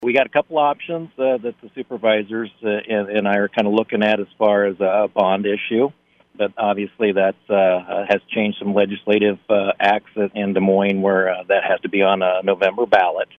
Marshall County Sheriff, Joel Phillips, joined the KFJB line to talk about what is needed, and what could be done.